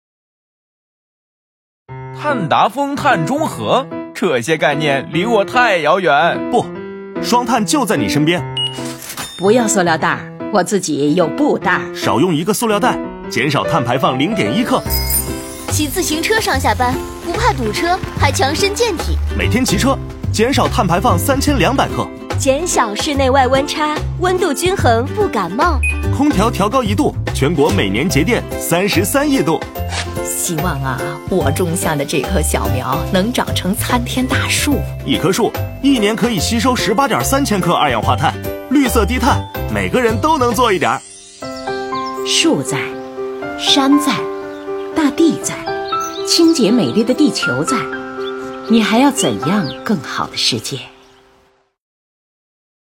广告文案作品《请不要让我变坏》和《“双碳”就在我身边》均已被总台广播公益广告制作中心制作成为广播公益广告，正在中央广播电台各频道及央广网播出，这也是中央广播电视总台广播公益广告制作中心举办的2022年广播公益广告优秀文案有奖征集活动获奖成果展示的一部分。